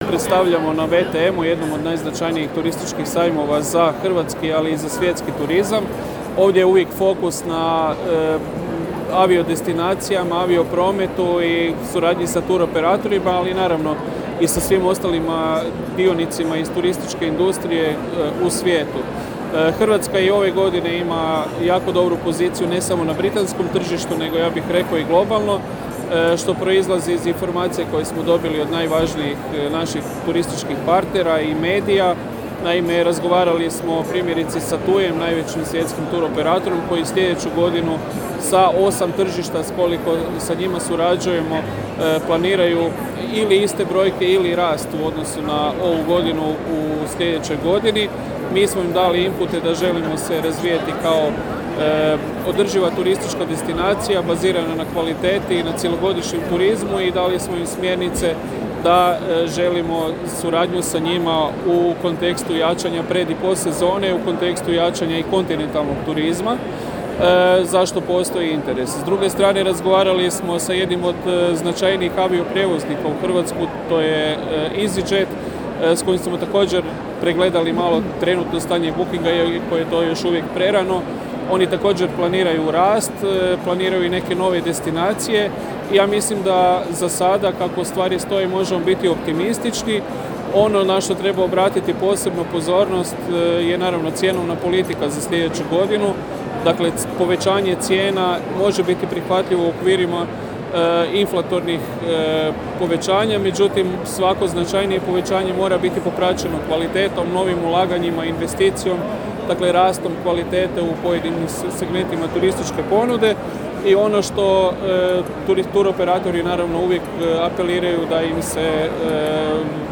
Izjavu direktora HTZ-a Kristjana Staničića poslušajte u nastavku: